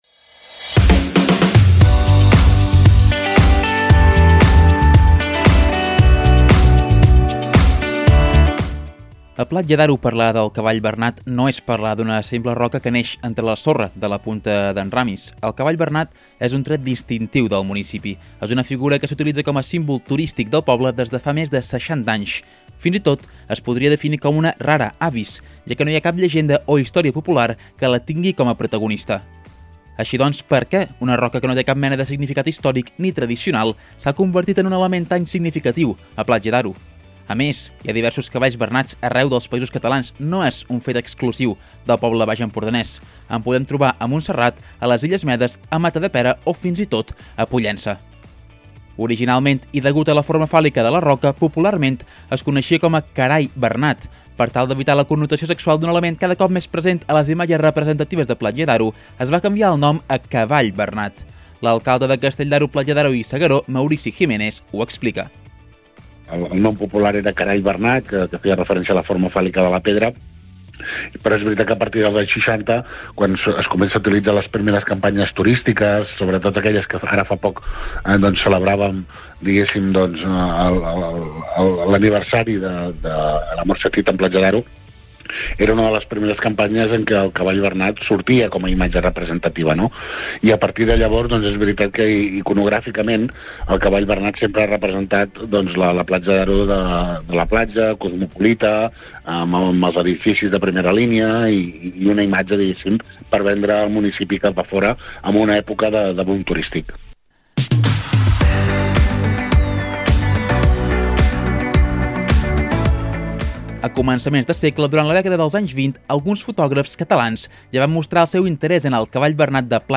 L’alcalde de Castell d’Aro, Platja d’Aro i S’Agaró, Maurici Jiménez, ho explica.
Són declaracions de Maurici Jiménez.